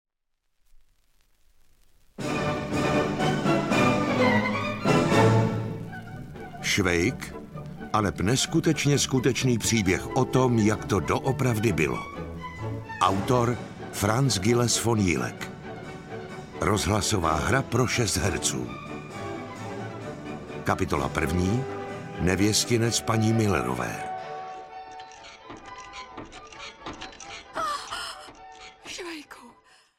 Odvážná rozhlasová hra pro šest herců seznámí posluchače šokujícím způsobem s neskutečně skutečným příběhem Josefa…
Ukázka z knihy